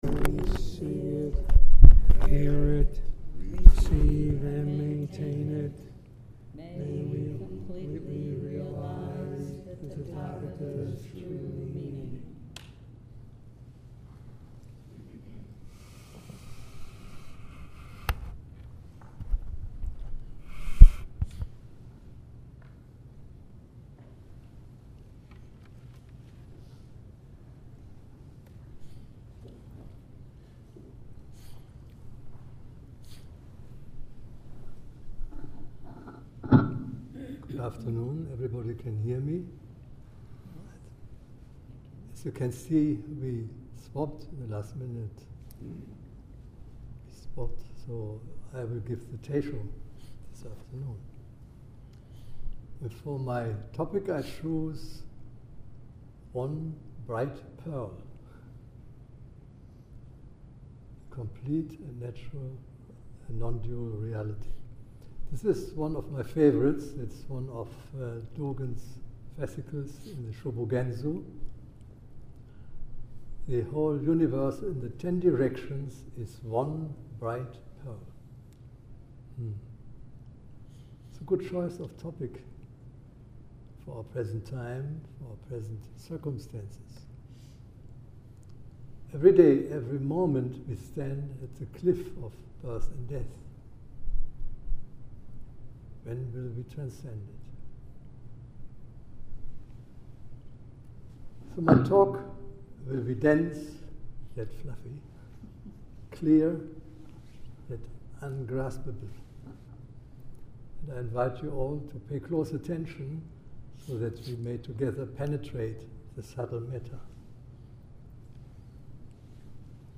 One Bright Pearl : Dharma Talk